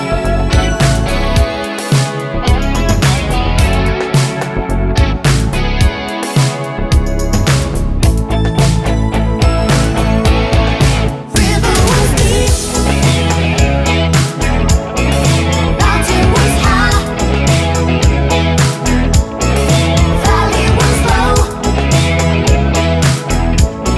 no Backing Vocals Duets 3:48 Buy £1.50